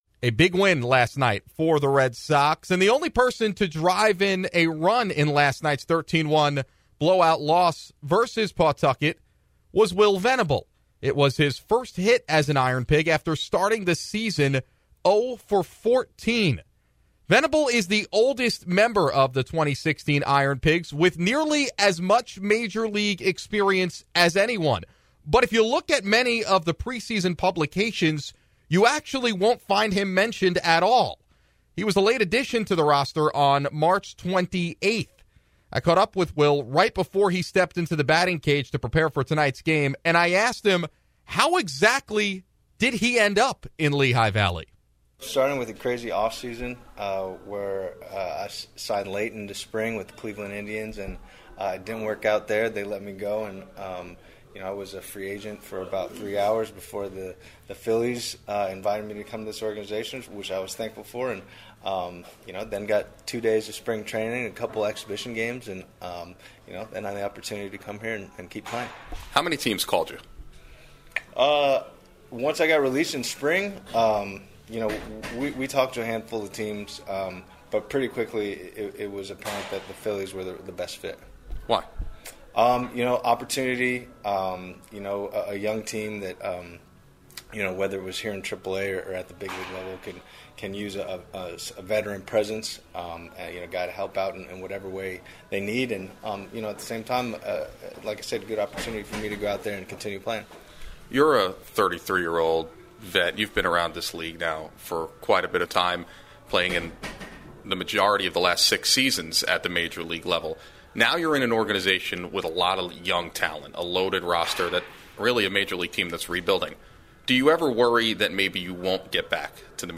INTERVIEW with Pigs OF Will Venable